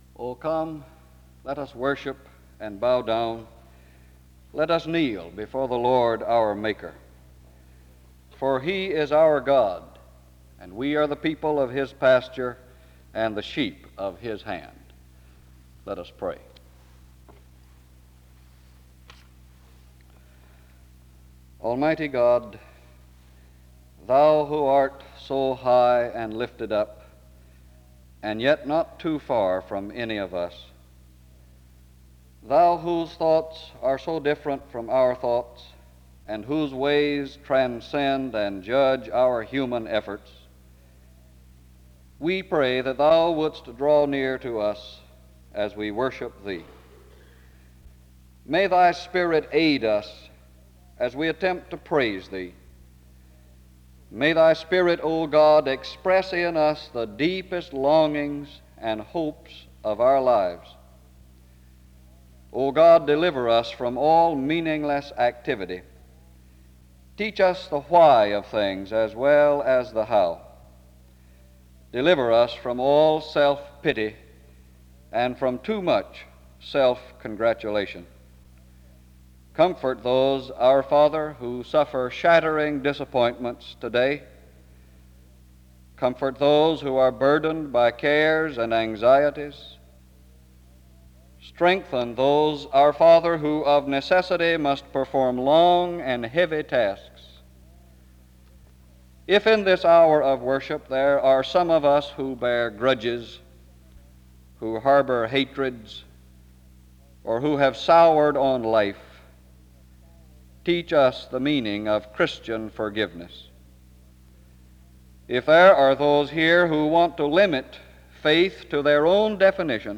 The service begins with a scripture reading and prayer from 0:00-2:55. Music plays from 3:05-6:35.